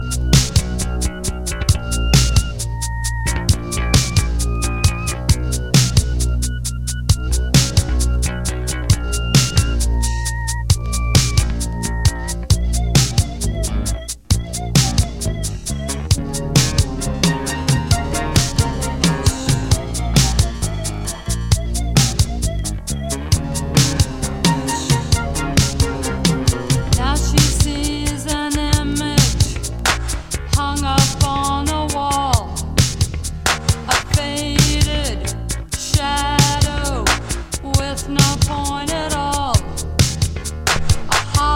a co-ed pop-rock band who